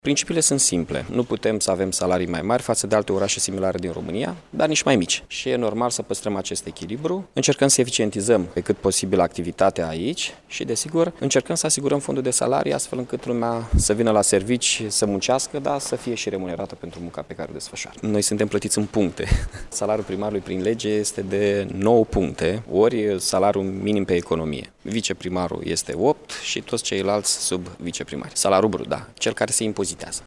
Cunatumul salariilor funcţionarilor din Primăria Iaşi va fi votat în viitoarea şedinţă a Consiliului Local, care va avea loc vineri, 28 iulie, a declarat primarul Mihai Chirica.